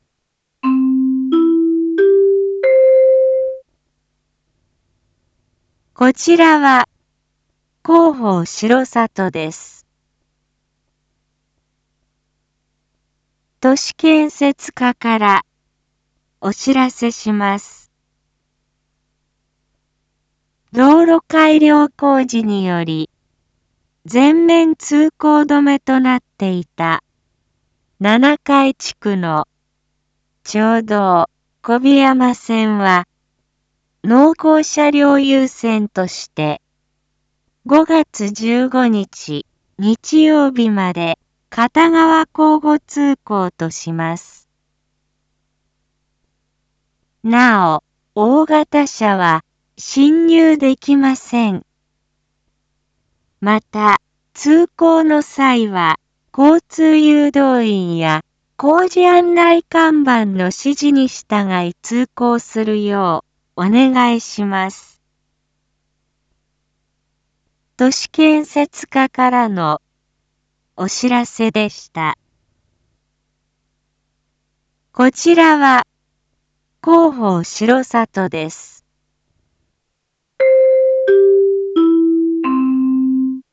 Back Home 一般放送情報 音声放送 再生 一般放送情報 登録日時：2022-04-16 07:01:24 タイトル：R4.4.16 7時放送分 インフォメーション：こちらは広報しろさとです。